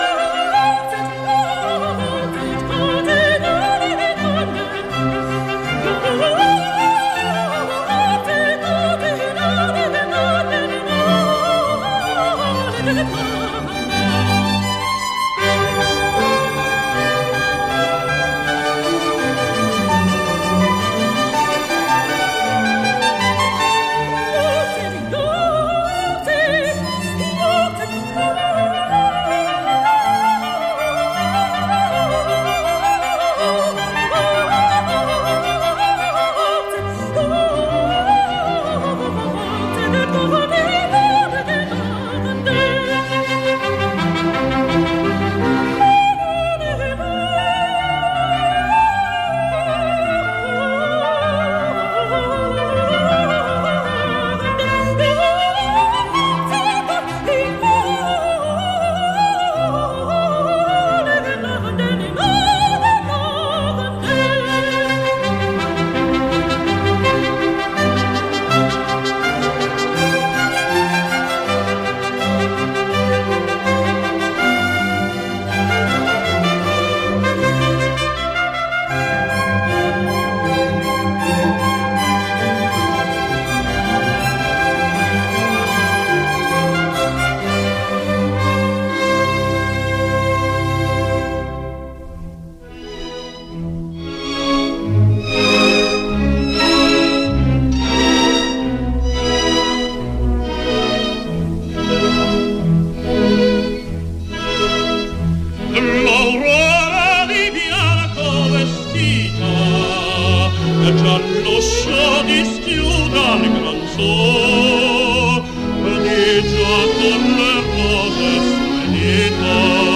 Un programme musical